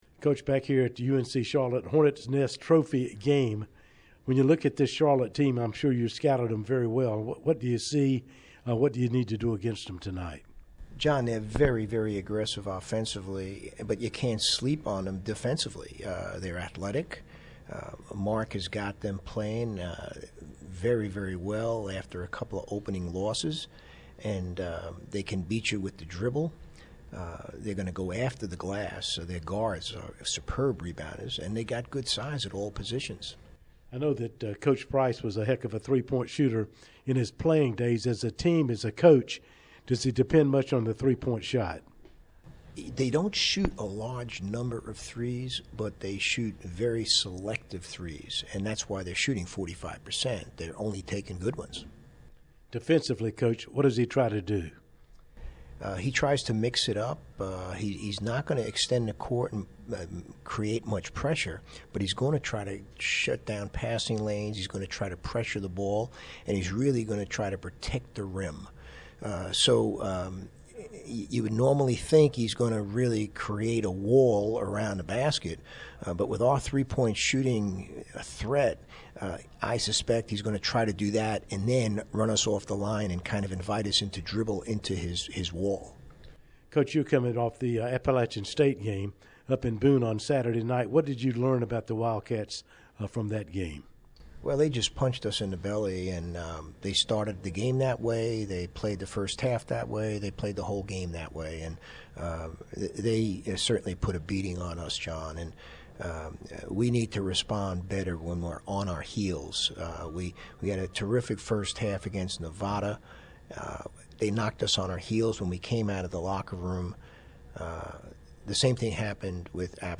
Pregame Interview